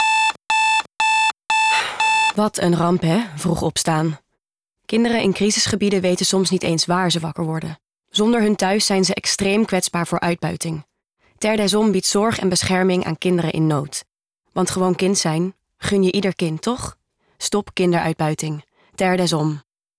Beluister de radiospot Onze missie Wij strijden tegen kinderarbeid, seksuele uitbuiting en we bieden noodhulp.